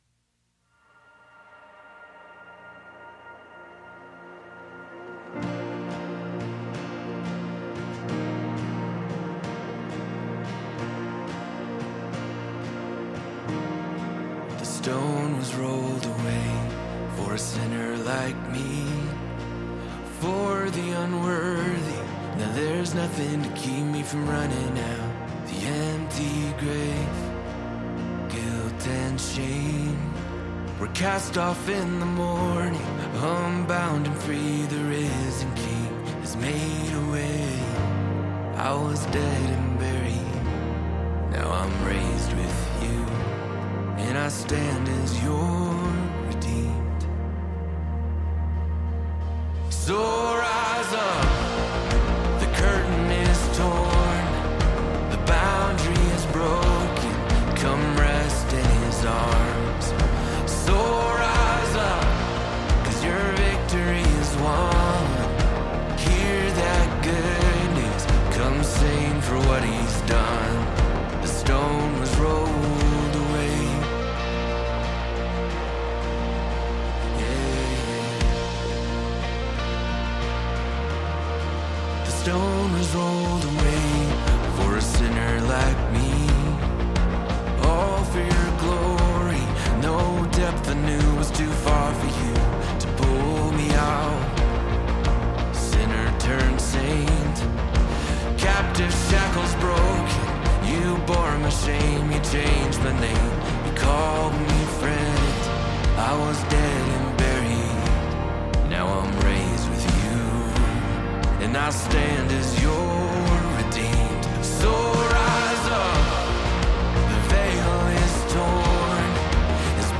ENJOY AN ORIGINAL SONG FROM OUR EASTER SERVICE.